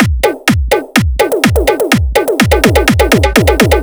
125 BPM Beat Loops Download